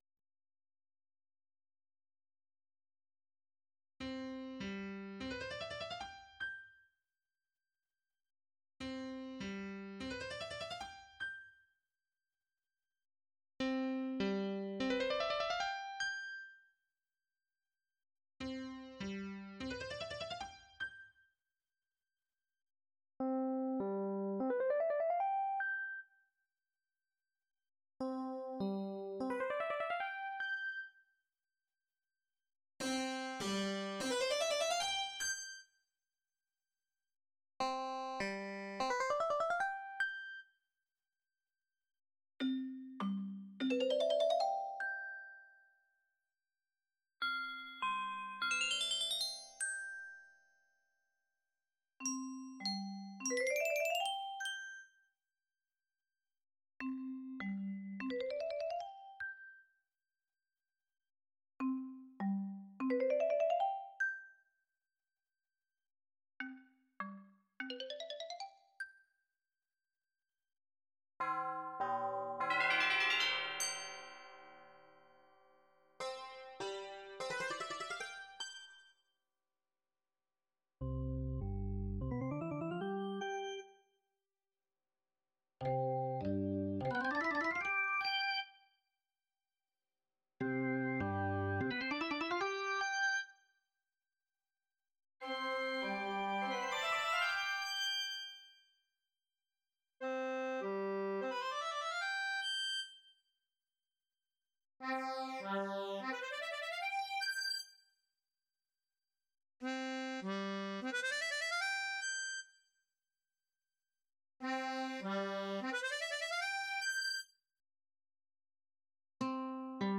Hangszín – kotta
A lejátszási idő 4.8 másodperc hangszínenként.
trumpet: lágy réz trombone: lágy réz tenor sax: réz oboe: tompított réz bassoon: halk, mély, réz
tubular bells: templomharang